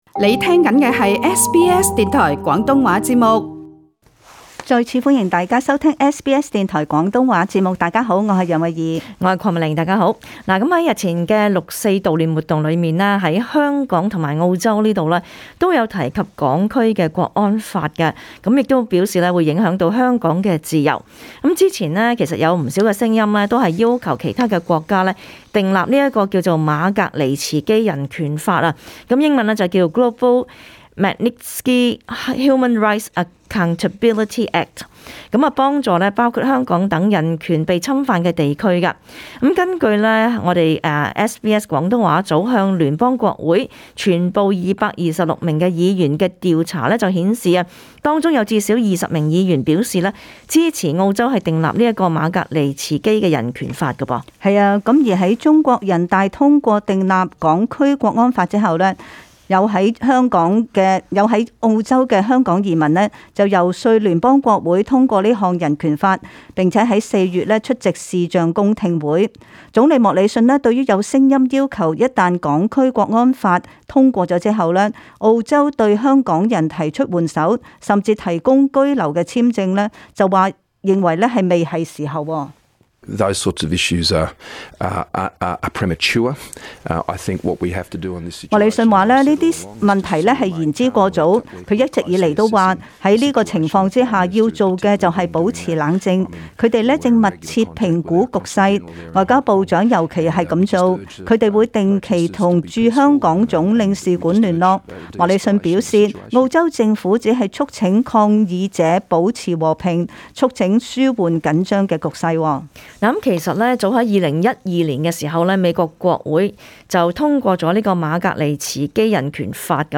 【时事报道】